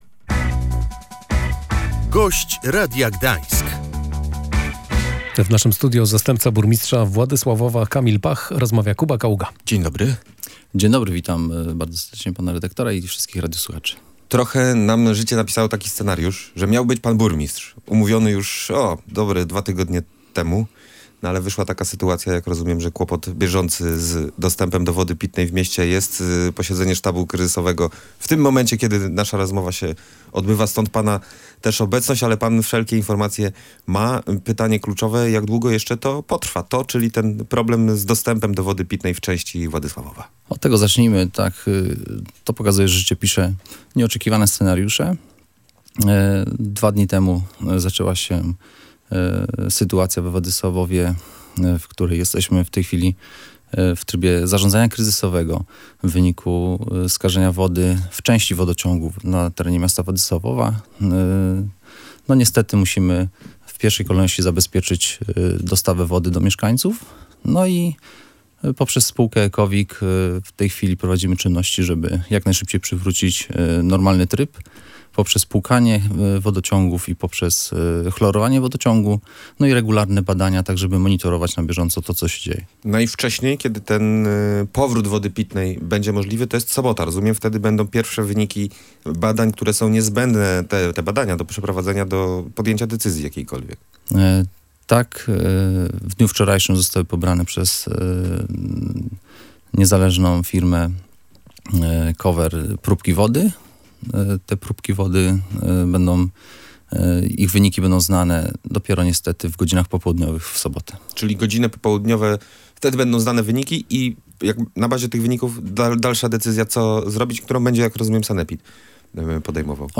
Sprawdzane są jednak różne scenariusze – wskazywał zastępca burmistrza Kamil Pach, który był Gościem Radia Gdańsk.